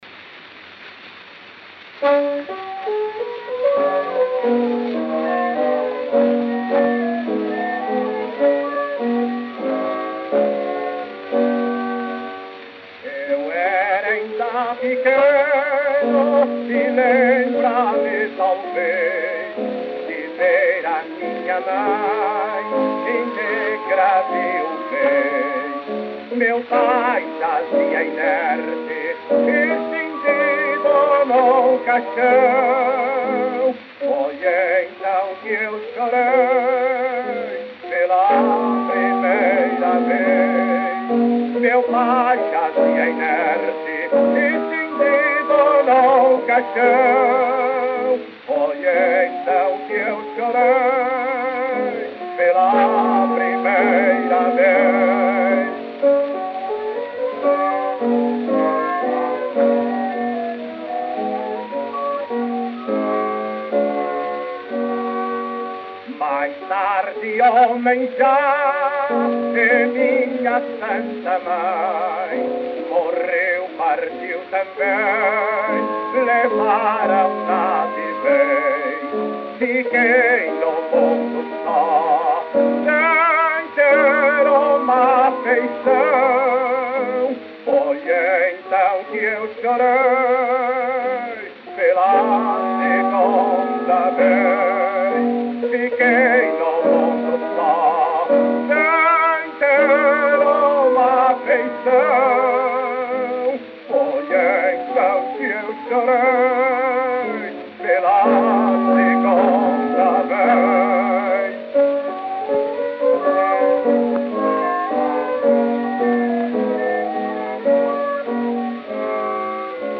Gênero: Fado.